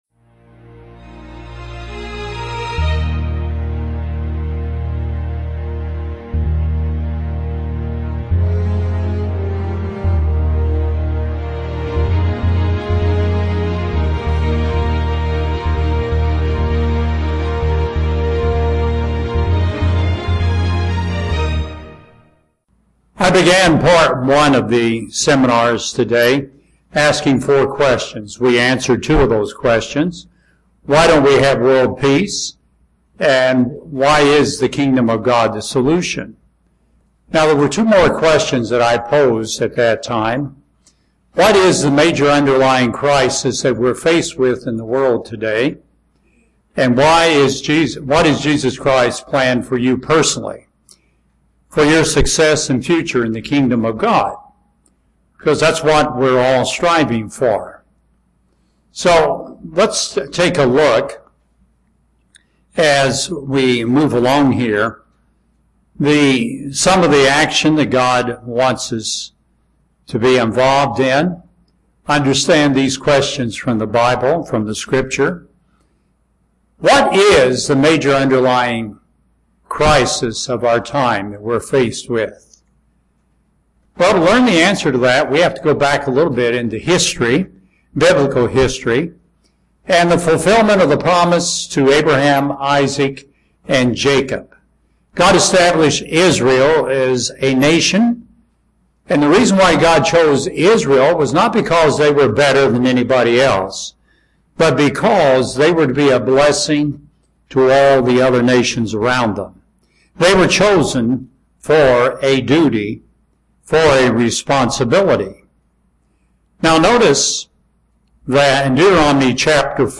What should we prepare for what is coming? Learn more in this Kingdom of God seminar.